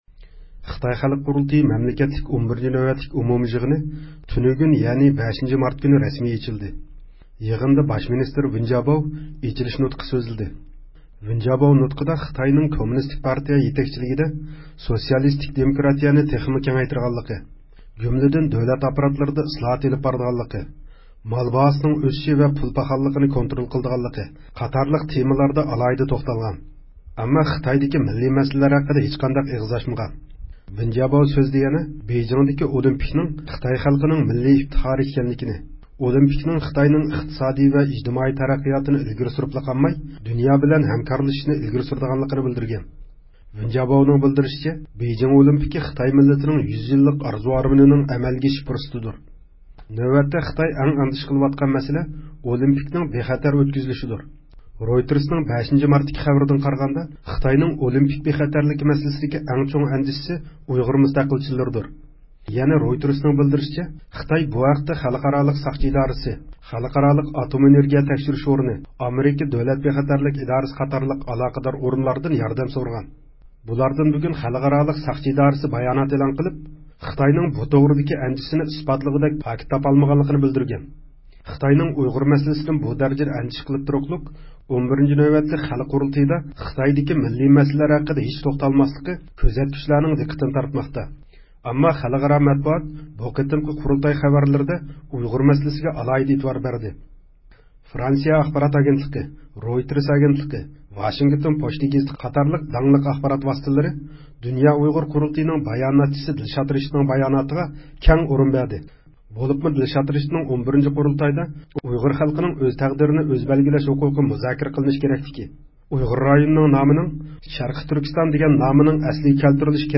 تېلېفونىمىزنى قوبۇل قىلغان دىلنار ئەھۋاللىشىشقىمۇ جۈرئەت قىلالمىدى.
بۇ ئاۋاز، گەپ قىلالايدىغان تۇرۇپ گەپ قىلمايۋاتقان ياكى گەپ قىلالمايۋاتقان ۋە ياكى قىلدۇرۇلمايۋاتقان، بۇ قېتىمقى ئۇيغۇر رايونىدىن كەلگەن ۋەكىللەردىن بىرى، گۈلنار ئابدۇللانىڭ ئاۋازىدۇر.